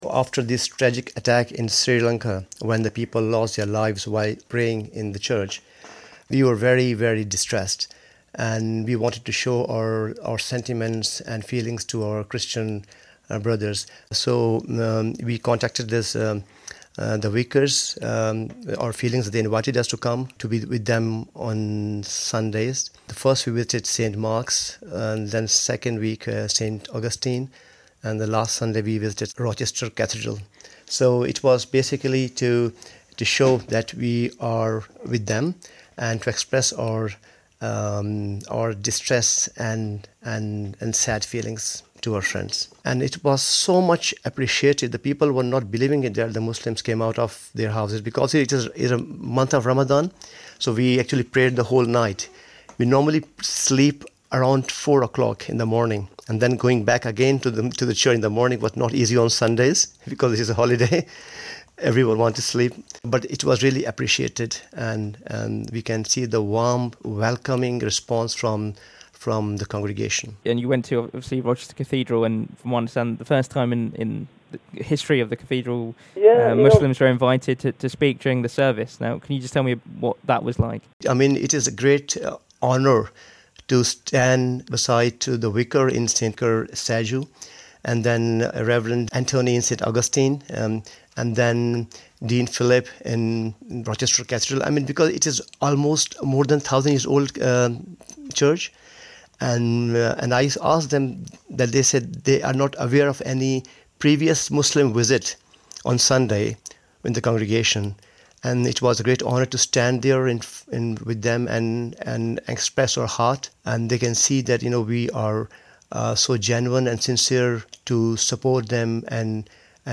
For the first time in the history of Rochester Cathedral a group of Muslims have been invited to speak during a Sunday Service.